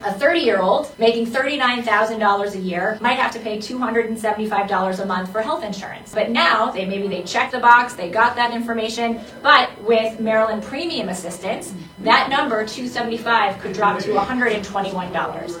Lierman said the health connection has ways to save Marylanders money, despite the absence of federal healthcare tax credits…